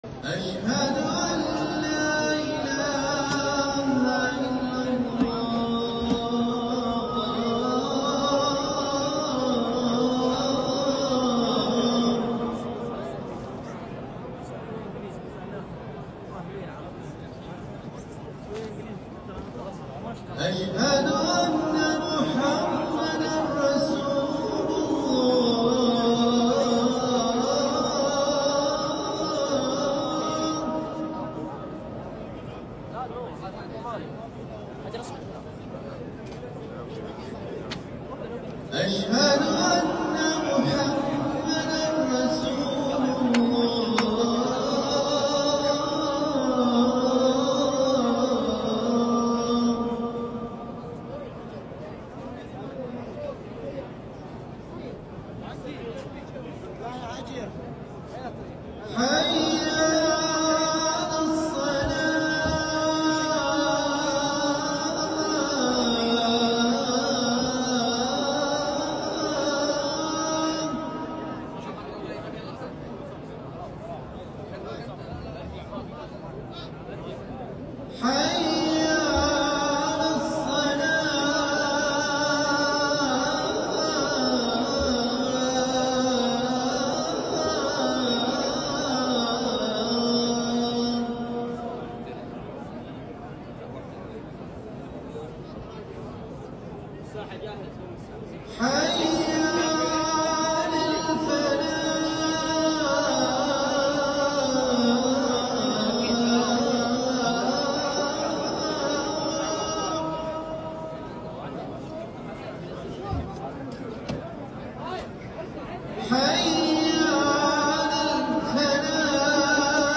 الأذان الأول لصلاة الفجر